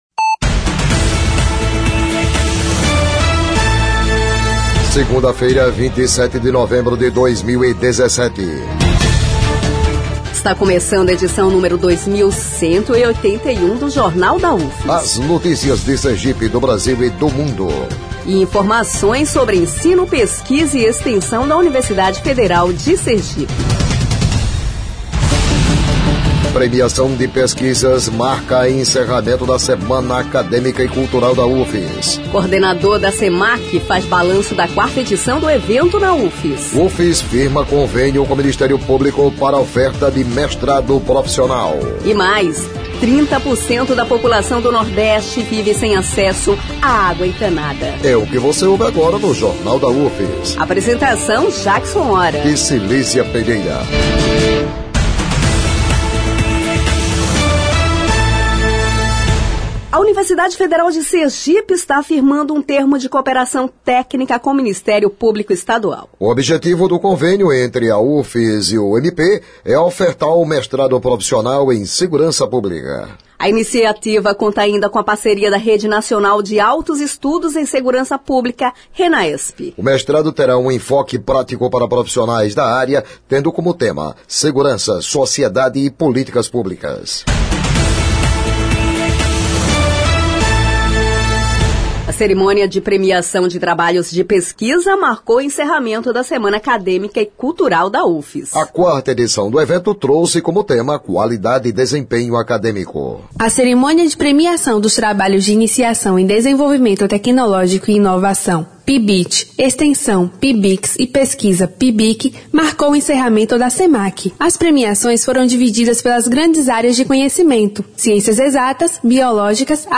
O Jornal da UFS desta segunda-feira, 27, repercute a Quarta Semana Acadêmica e Cultural da UFS.